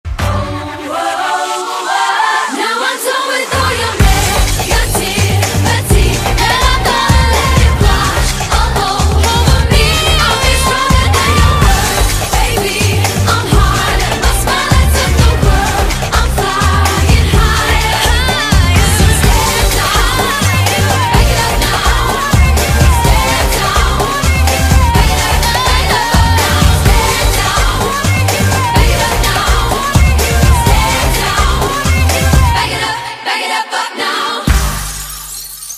поп
громкие
женский вокал
dance